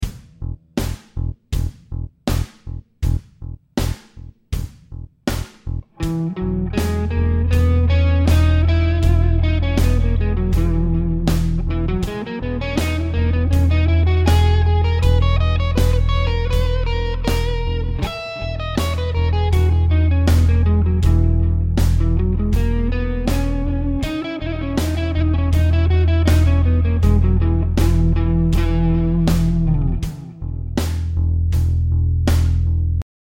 The bass is just playing the E note in all examples.
Lydian
I like the fourth mode, Lydian, it has a great floaty feel to it.
With the focus on the E it sounds completely different from B Major